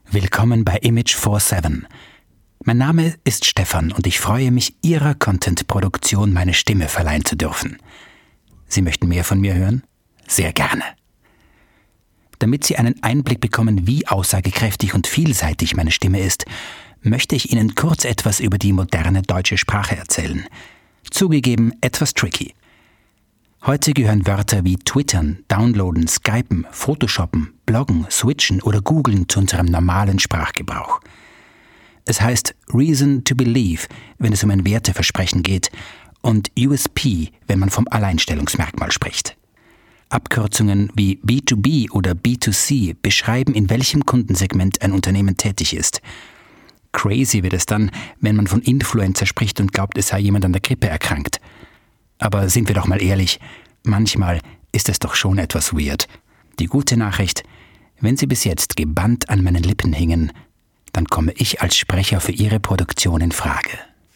wienerisch
Sprechprobe: eLearning (Muttersprache):